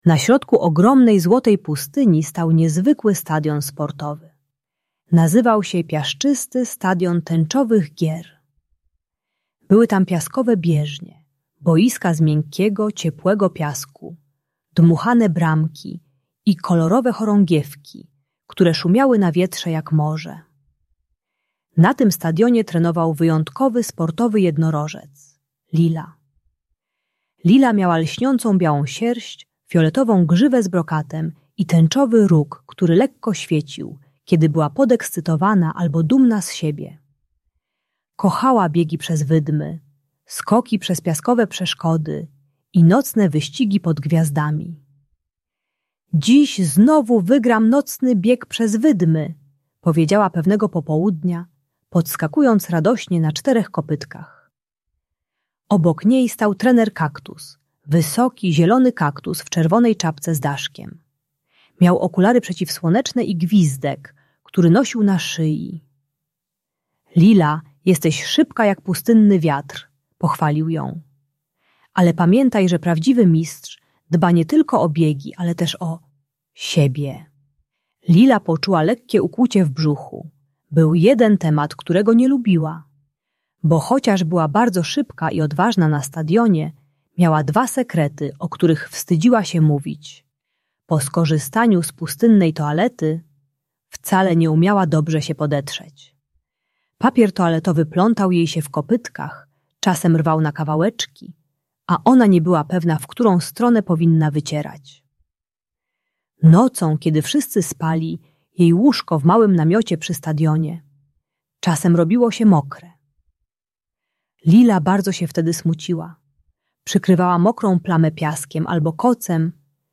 Uczy techniki "składanej chorągiewki" do podcierania (od przodu do tyłu) oraz rytuału "Nocnego Dyżuru" - 3 kroków przed snem zapobiegających moczeniu nocnemu. Darmowa audiobajka o treningu czystości.